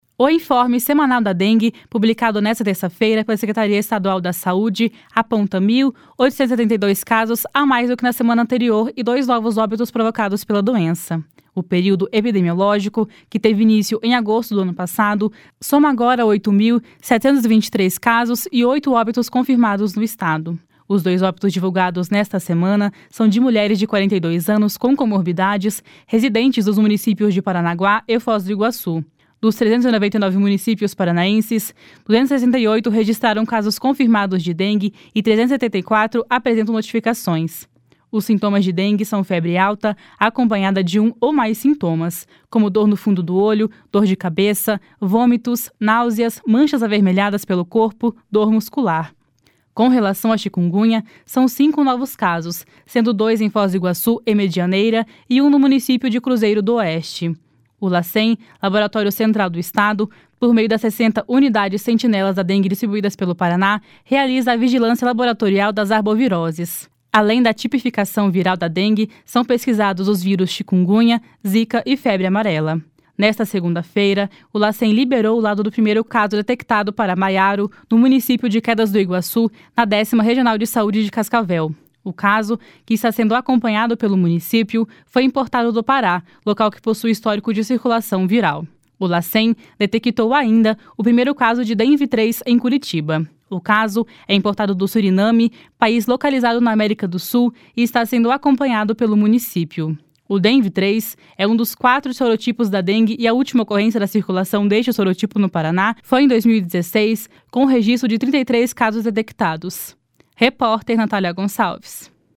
INFORME SEMANAL DA DENGUE.mp3